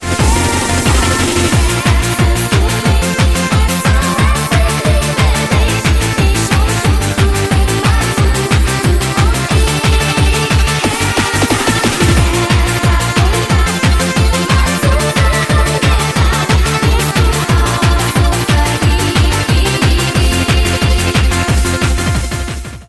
• Качество: 128, Stereo
электроника